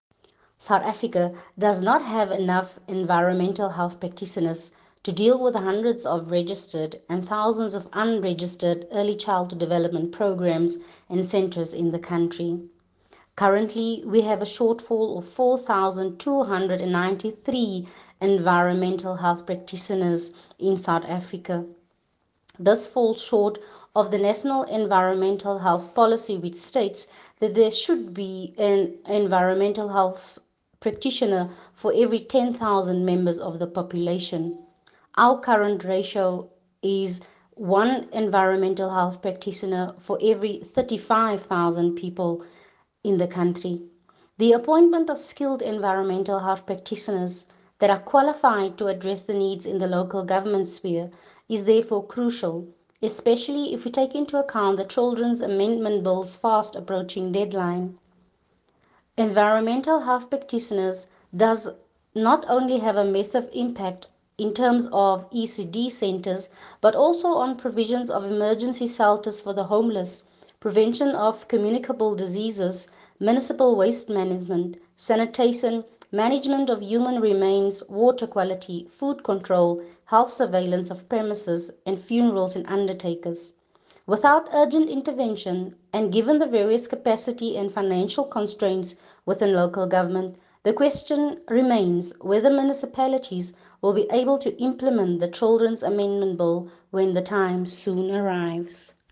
soundbite by Gizella Opperman MP.